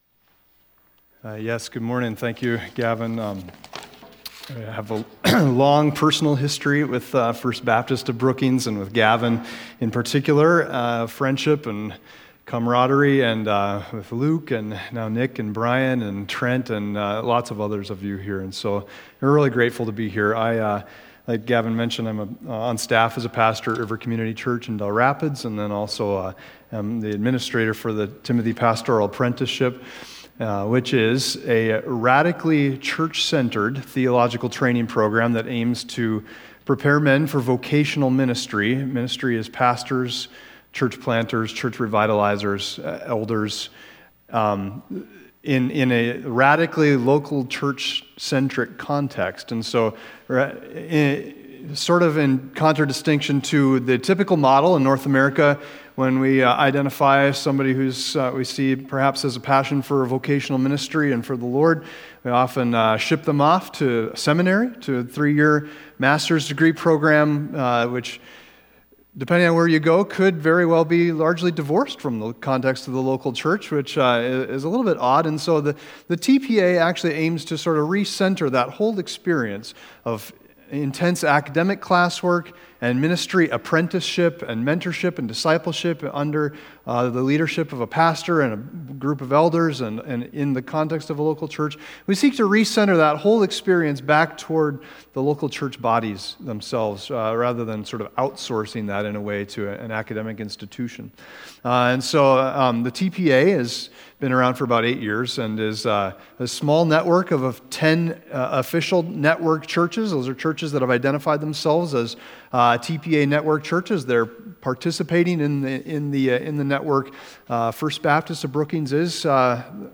Other Passage: Zephaniah 3:14-17 Service Type: Sunday Morning Zephaniah 3:14-17 « That You May Know Distinguishing Qualities of the Blessed